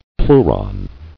[pleu·ron]